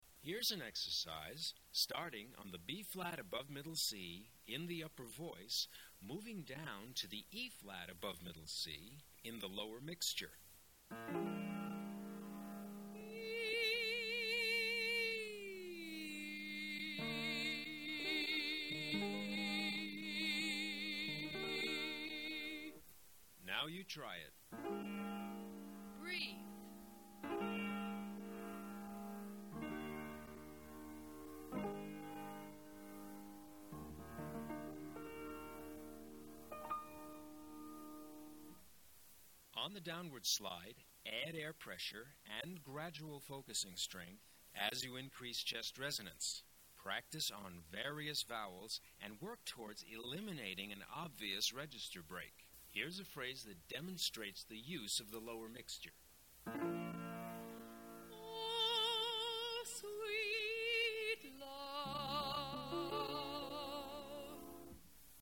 explain how to negotiate the mix (Modes 1 and 2) register in female and male music theater singing.